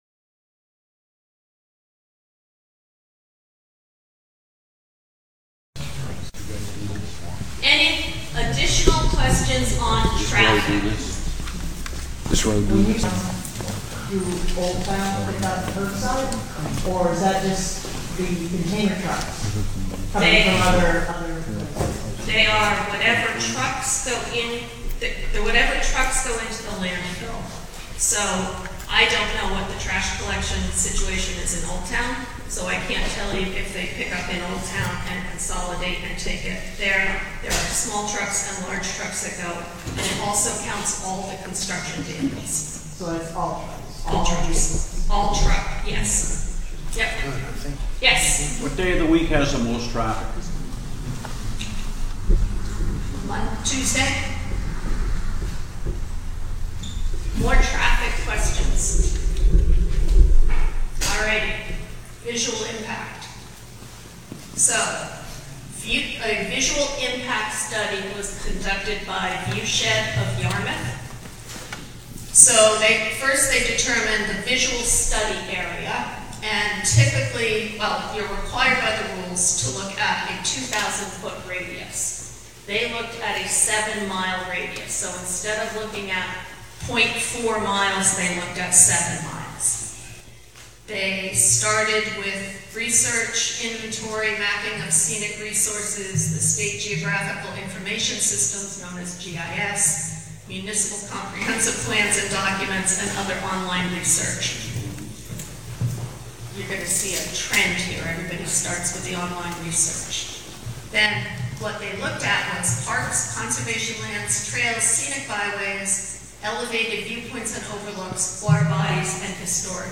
Juniper Ridge expansion plan meeting Nov 21, 2024 32 minutes (meeting in progress)